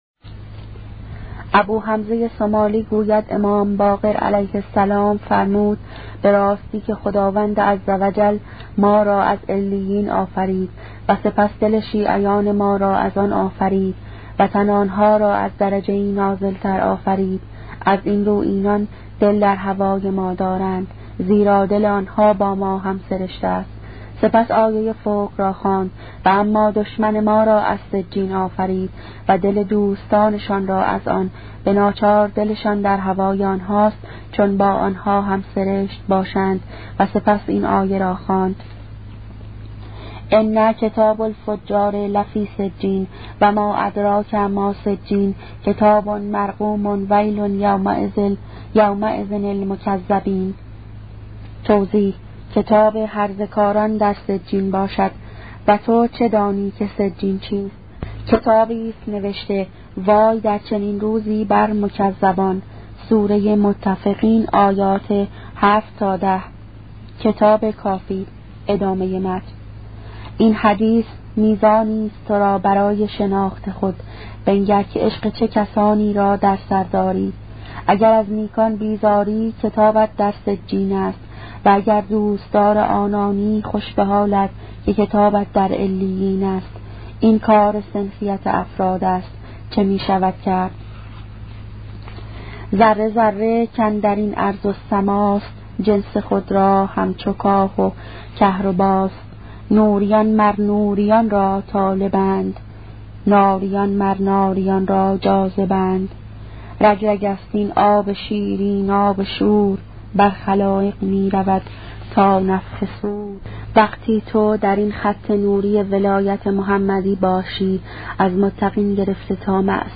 کتاب صوتی عبادت عاشقانه , قسمت دوازدهم